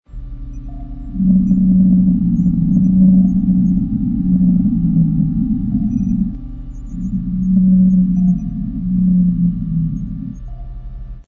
Sound Effects (SFX) were created to paint the scene inside and surrounding the Submarine.
Natural Soundescapes
SND_NAT2_BLUE_WHALE_10_sec.mp3